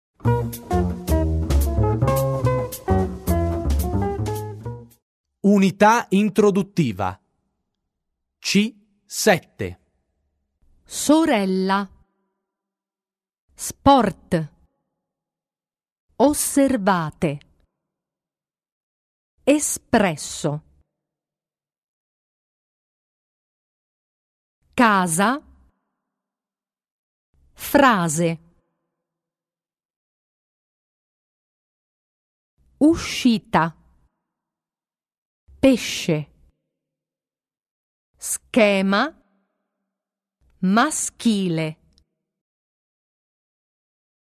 Poslech: výslovnost 2